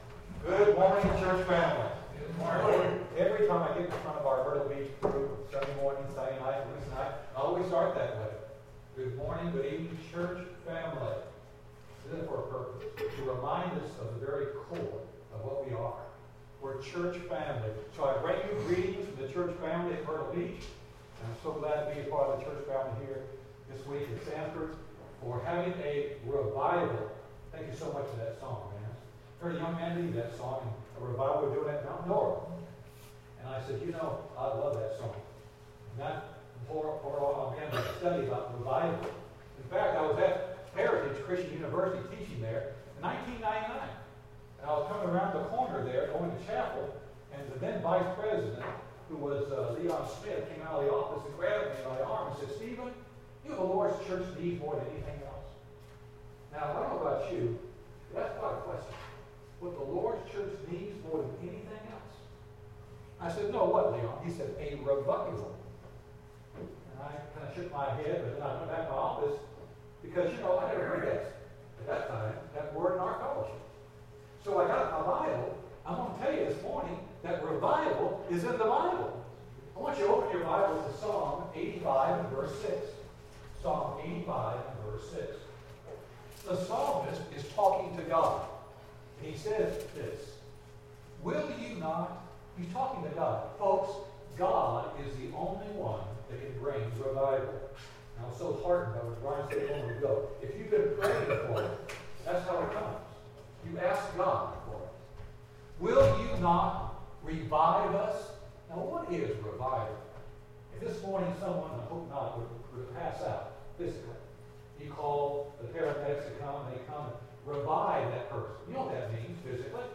Service Type: Gospel Meeting